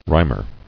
[rim·er]